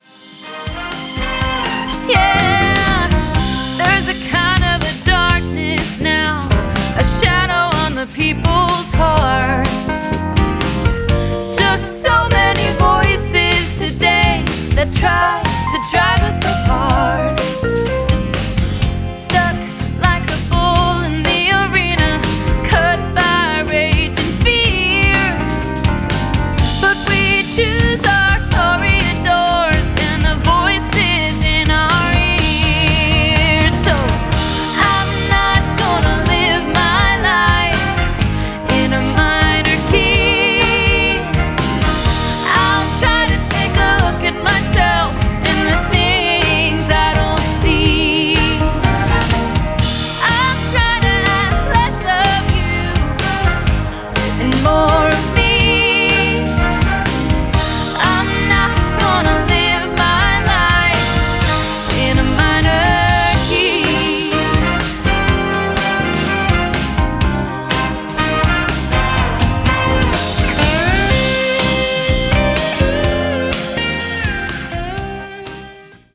backed once again by the top Nashville studio musicians.
up-tempo, horns-driven title track